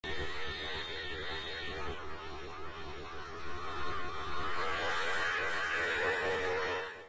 radar_idle.ogg